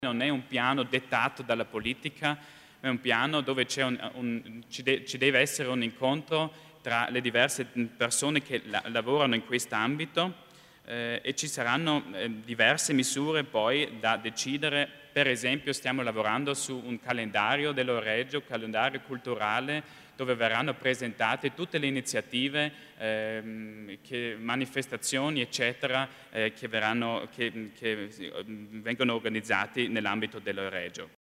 L'Assessore Achammer spiega i nuovi progetti in ambito Euregio